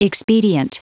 Prononciation du mot expedient en anglais (fichier audio)
Prononciation du mot : expedient